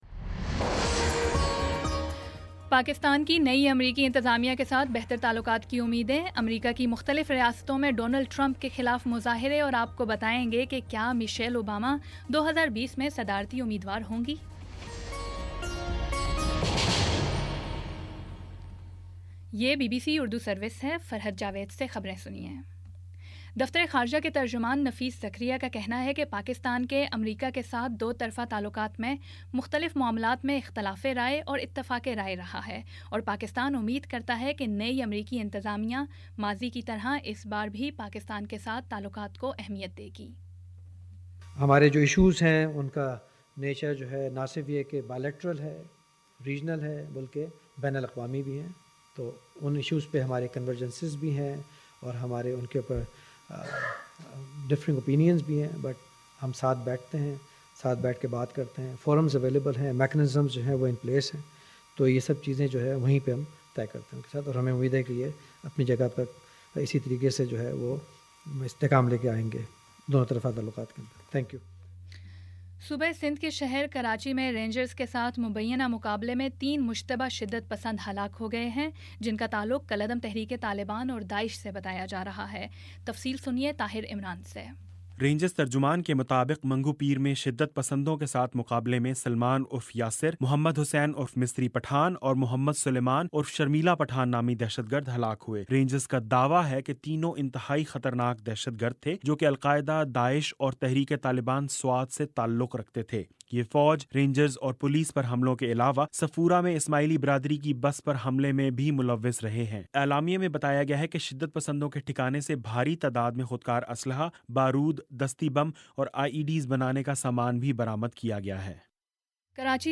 نومبر 10 : شام پانچ بجے کا نیوز بُلیٹن